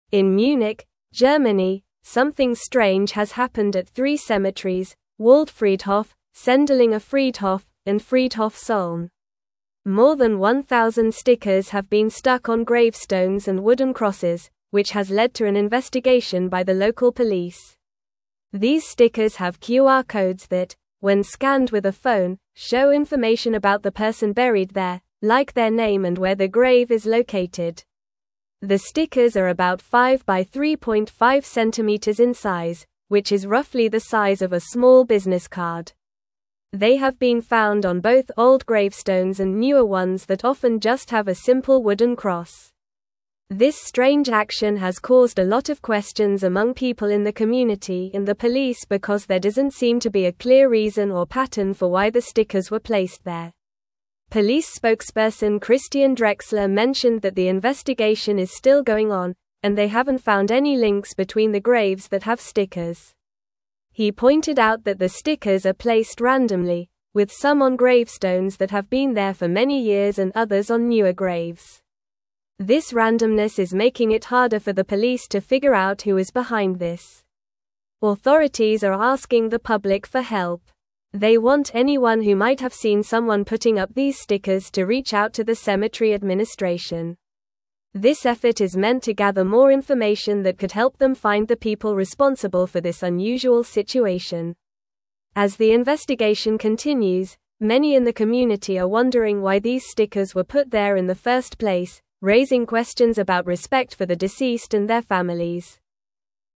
Normal
English-Newsroom-Upper-Intermediate-NORMAL-Reading-QR-Code-Stickers-Appear-on-Gravestones-in-Munich.mp3